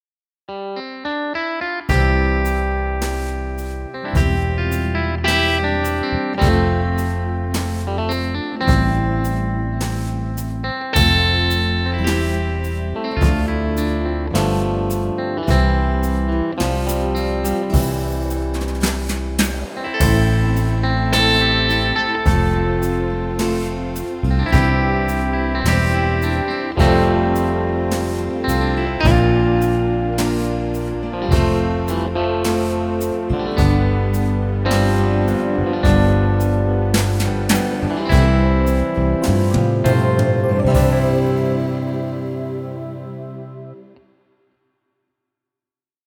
Intro/endings works in Major.